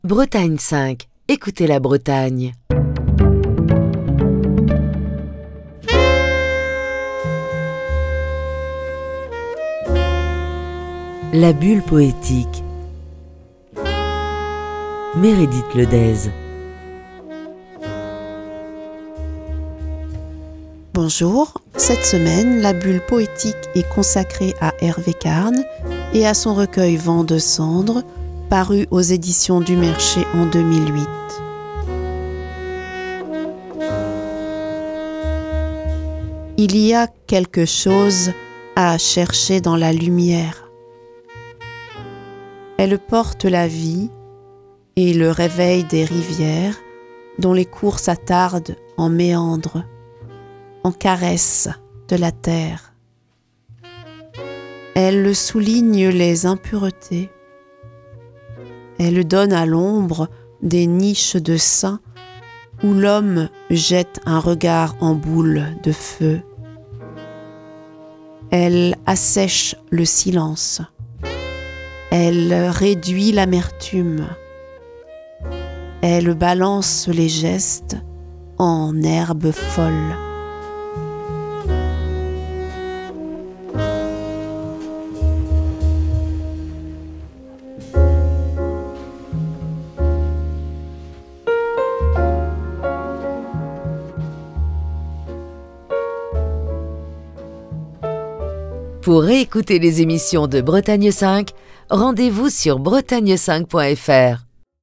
la lecture de textes